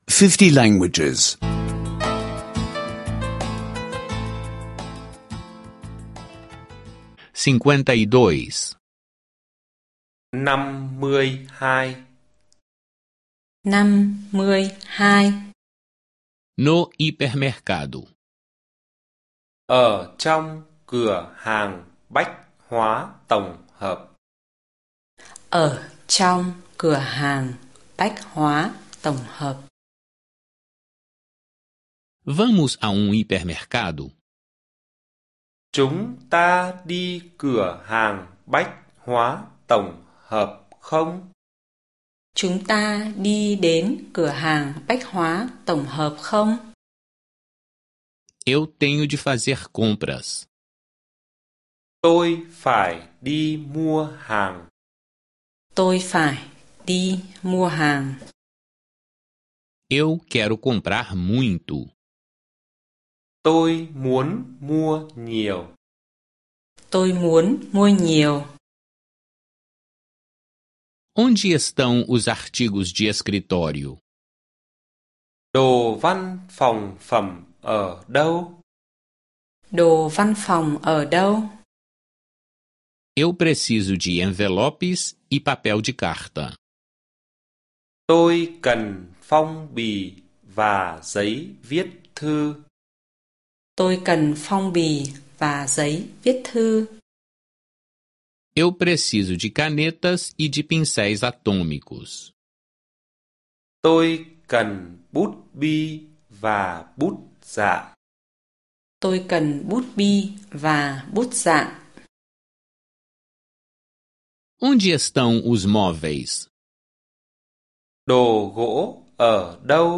Aulas de vietnamita em áudio — escute online